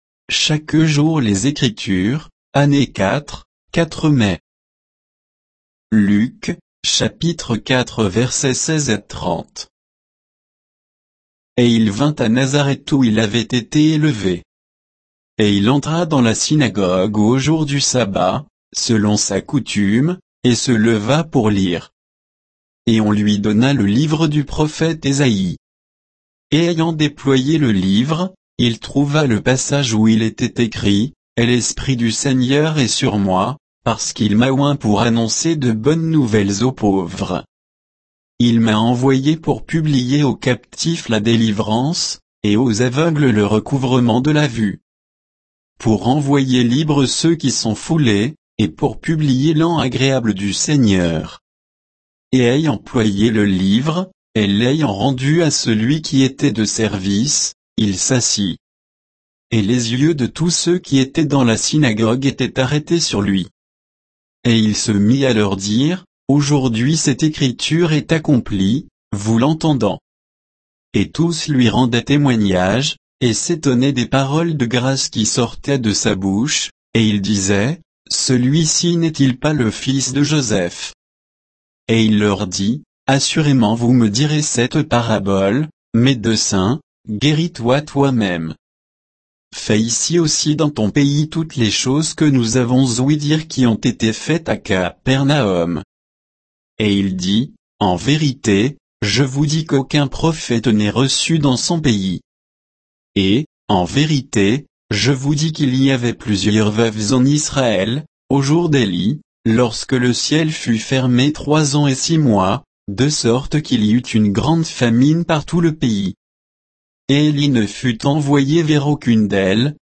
Méditation quoditienne de Chaque jour les Écritures sur Luc 4